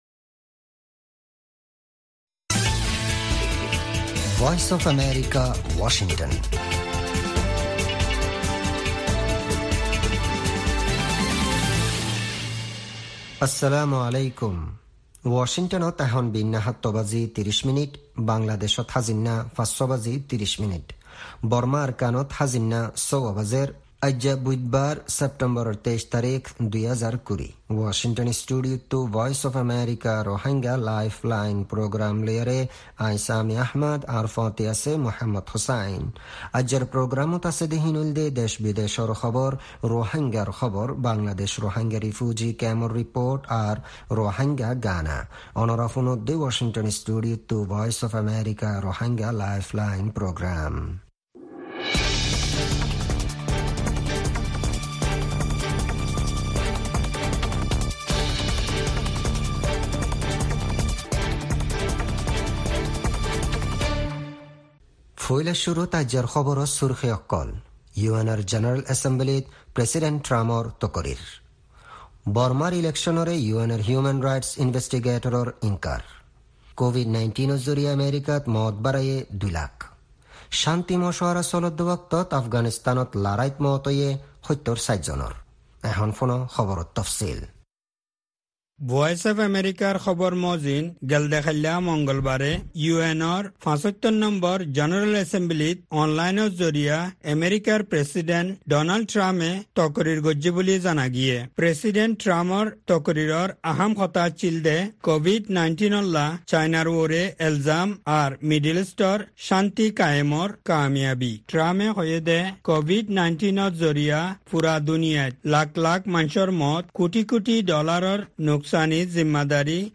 Rohingya Broadcast